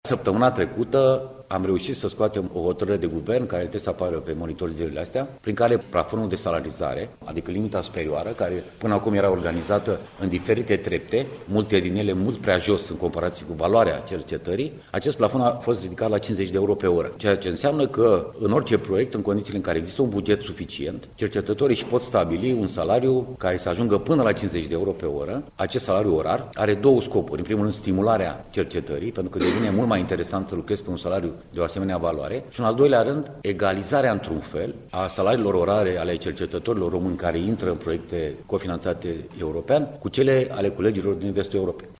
40.000 de cercetători din România, care primesc sau vor primi până la sfârşitul anului retribuţii în cadrul proiectelor de cercetare finanţate de bugetul de stat şi/sau cofinanţate de Uniunea Europeană, vor putea ajunge la un nivel maxim de salarizare de 50 de euro/oră – a anunţat astăzi, la Galaţi, ministrul Cercetării şi Inovării, Lucian Georgescu: Săptămâna trecută am reuşit să scoatem o hotărâre de guvern care trebuie să apară pe monitor zilele astea prin care plafonul de salarizare, adică limita superioară, care până acum era organizată în diferite trepte, multe dintre ele mult prea jos în comparaţie cu valoarea cercetării, acest plafon a fost ridicat la 50 euro/oră, ceea ce înseamnă că în orice proiect, în condiţiile în care există un buget suficient, cercetătorii îşi pot stabili un salariu care să ajungă până la 50 de euro/oră.